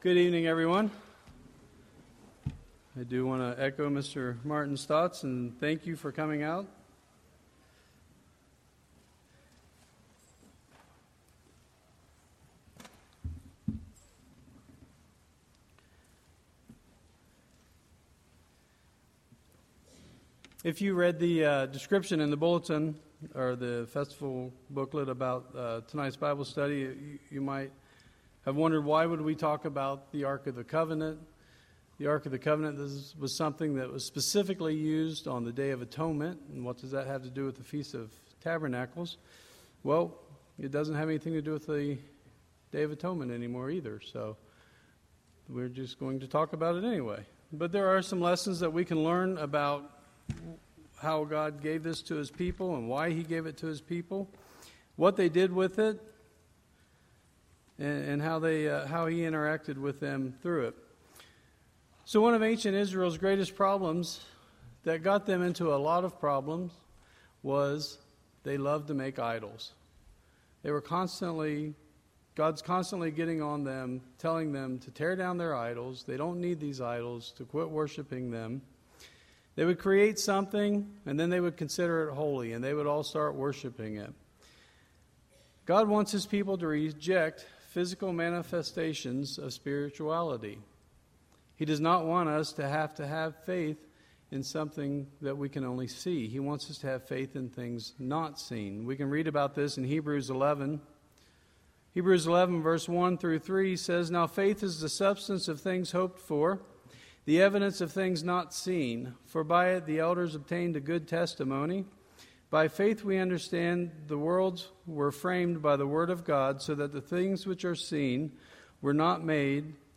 This sermon was given at the Snowshoe, West Virginia 2018 Feast site.